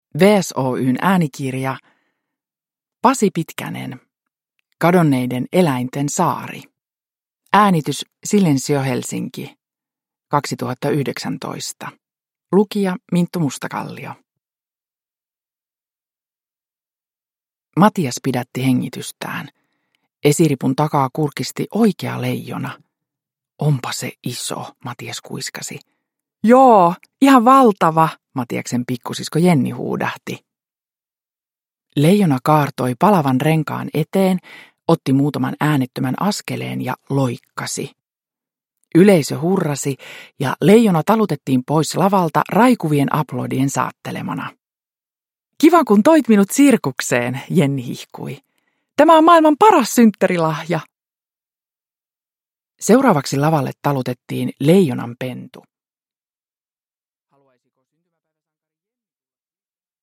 Kadonneiden eläinten saari – Ljudbok – Laddas ner
Uppläsare: Minttu Mustakallio